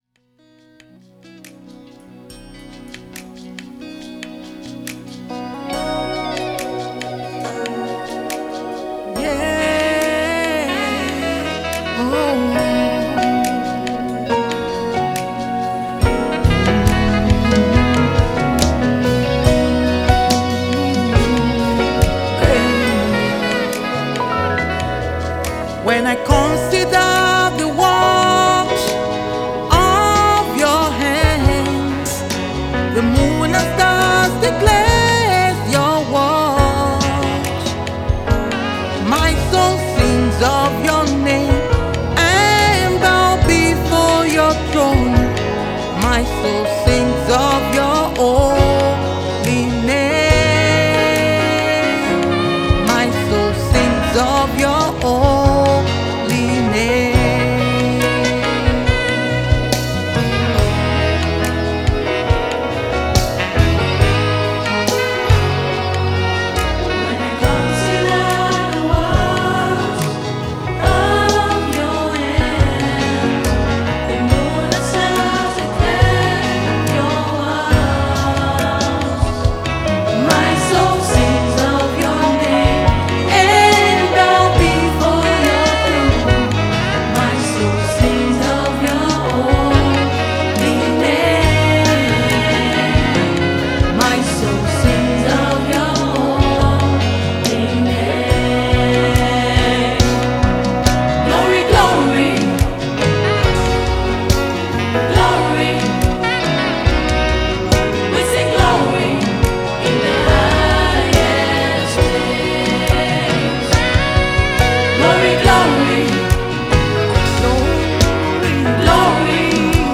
GOSPEL No Comments
With heartfelt expression and soaring melodies